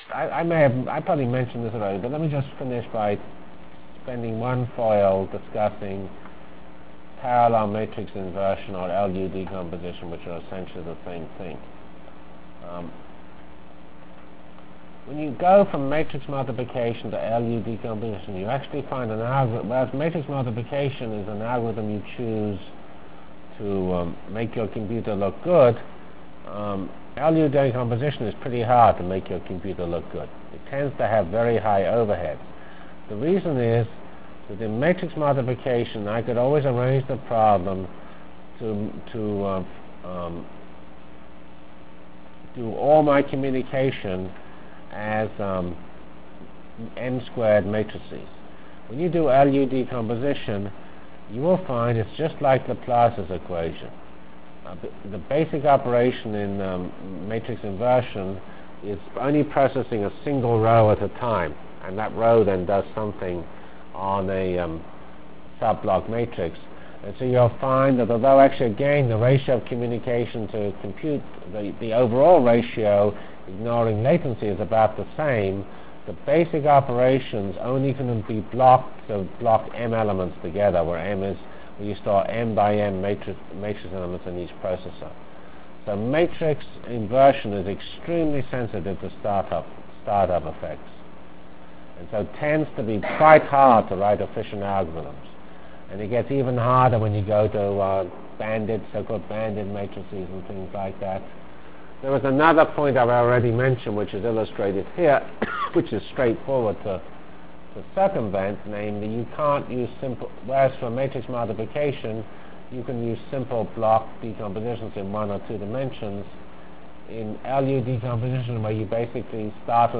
From CPS615-Linear Programming and Whirlwind Full Matrix Discussion Delivered Lectures of CPS615 Basic Simulation Track for Computational Science -- 5 Decemr 96.